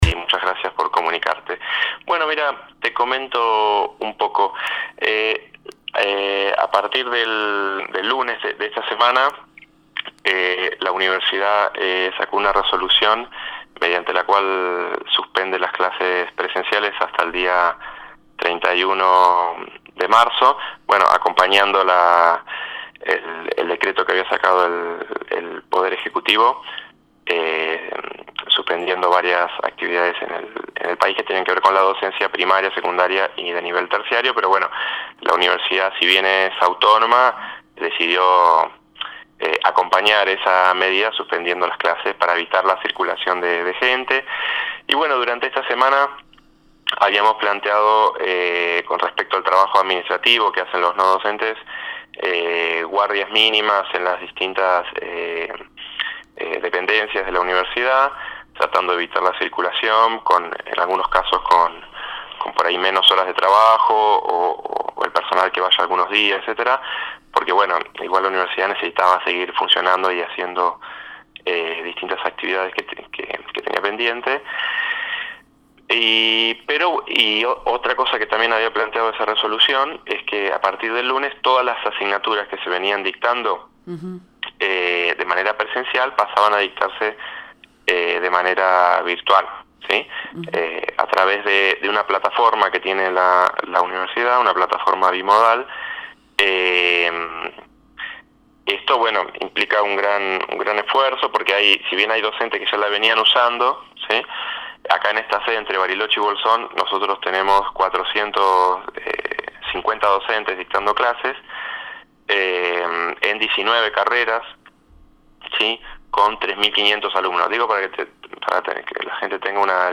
En diálogo con Proyecto Erre